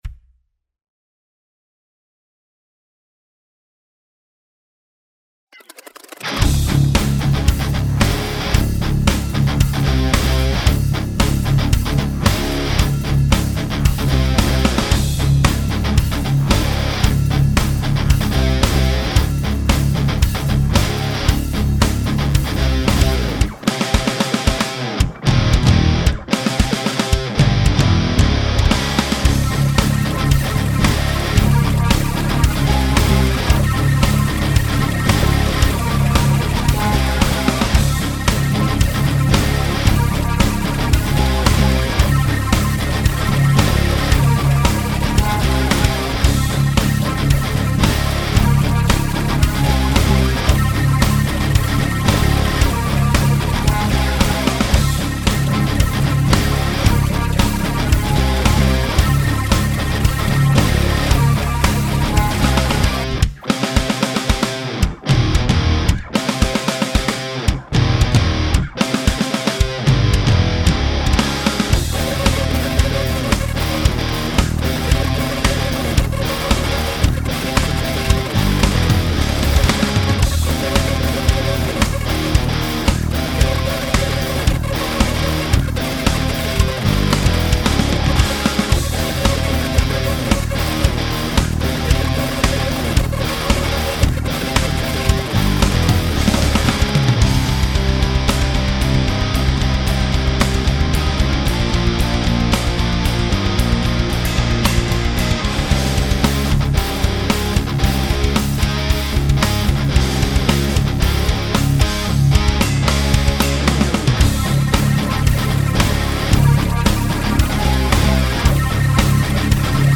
C'est la version instru pré mixée par bibi, pour le moment
Batterie SD2, les grattes dans la Orange Rockeverb (reprises par le SM57)...
Ou couper des low mids, car ça sonne un peu brouillon dès que ça PM, alors que le son est hyper clair et cool autrement.